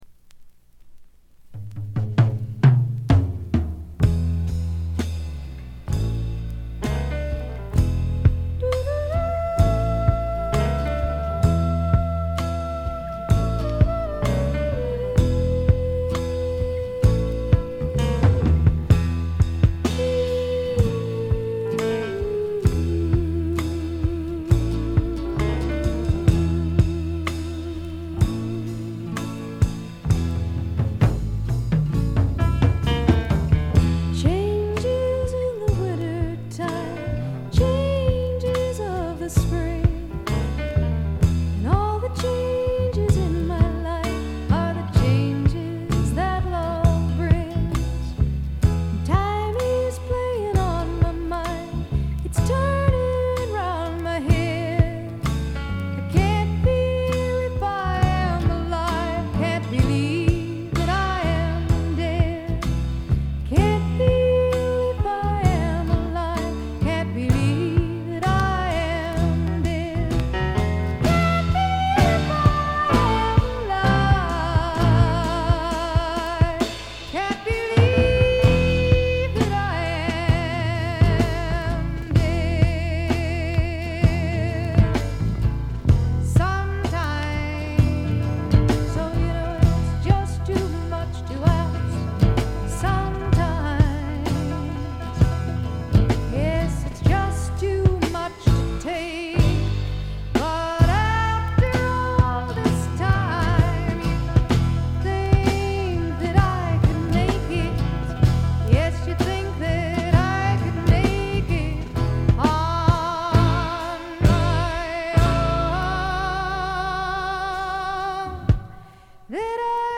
ところどころでチリプチ。大きなノイズはありません。
試聴曲は現品からの取り込み音源です。